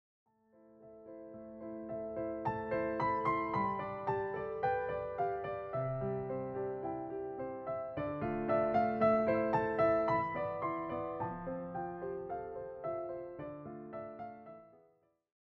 all given a solo piano treatment.